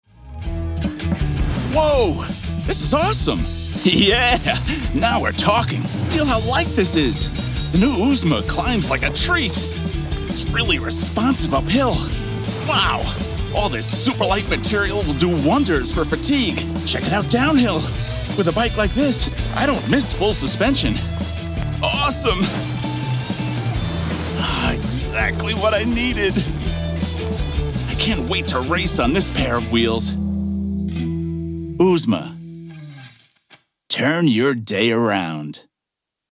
Adult, Mature Adult
Has Own Studio
From cool and relaxed to friendly and conversational to upbeat and energetic, let me bring your project to life with just the right voice it needs.
standard us | natural
COMMERCIAL 💸